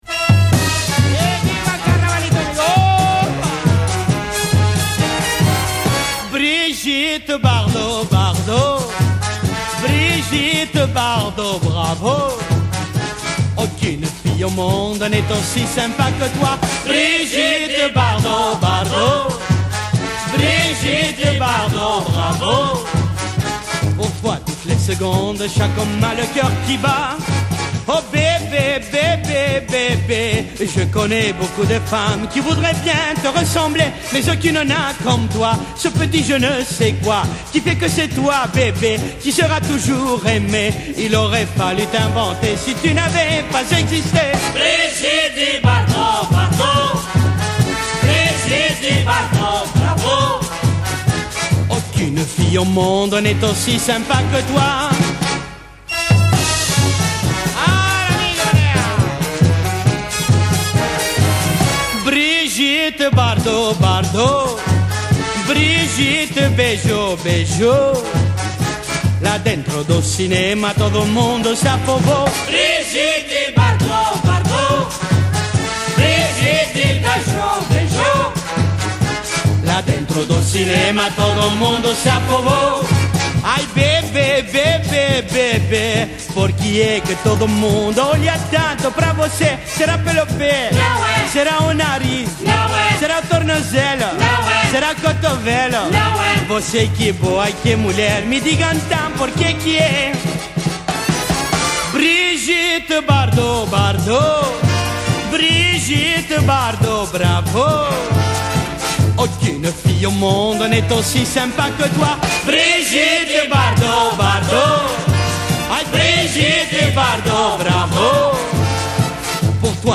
Le chanteur d’origine turque chantera